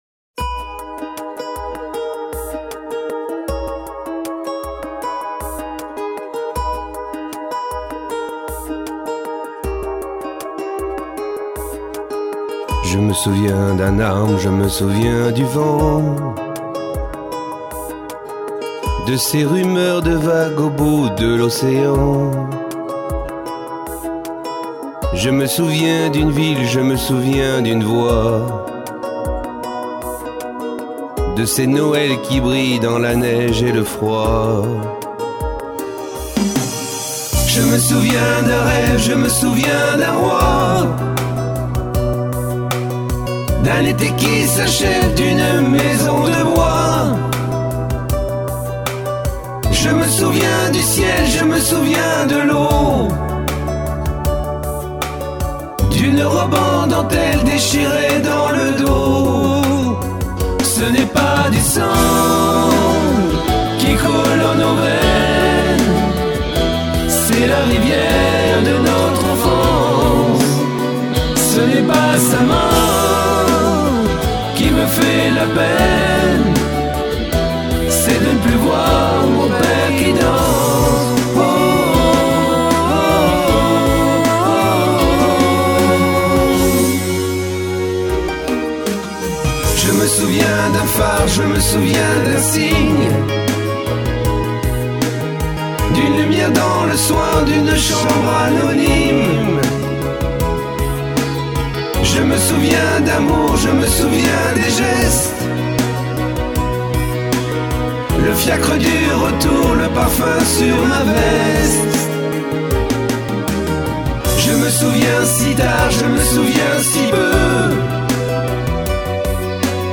Tutti